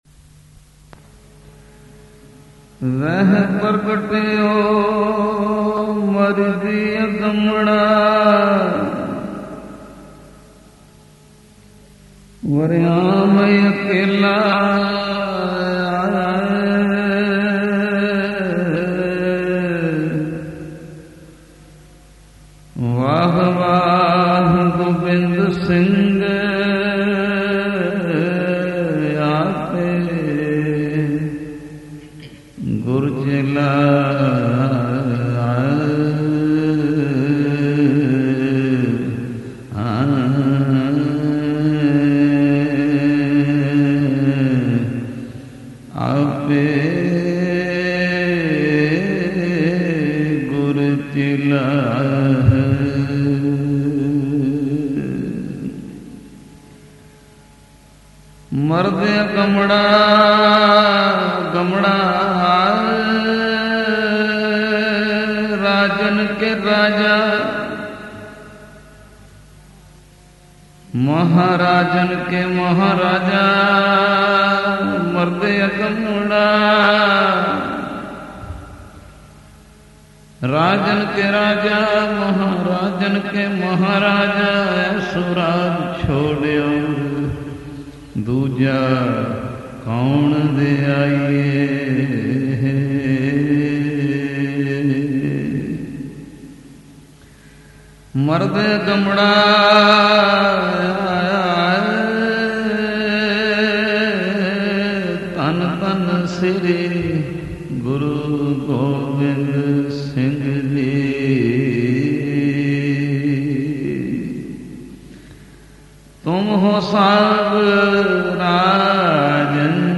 Genre: Shabad Gurbani Kirtan with katha